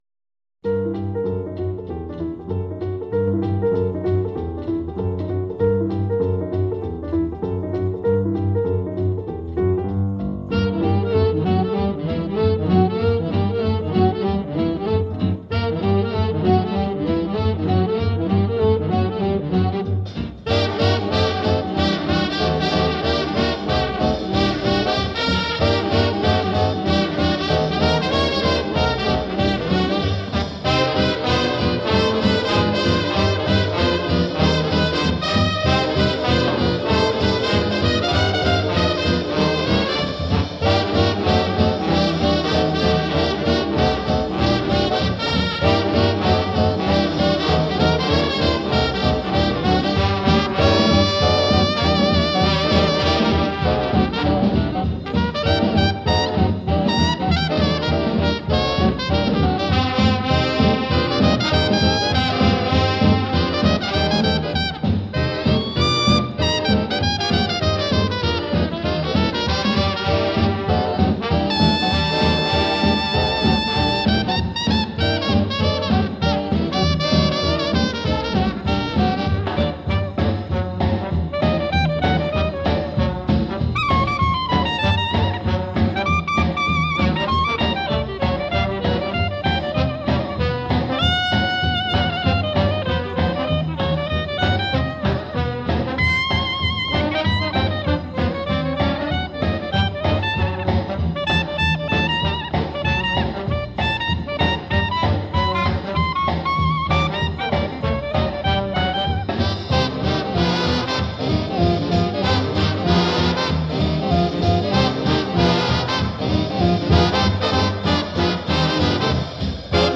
Jazz After Dark spans the gamut, from roots in boogie-woogie, blues, and ragtime through traditional and straight-ahead jazz, soul jazz, bossa nova, and more.